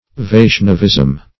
Vaishnavism \Vaish"na*vism\, n.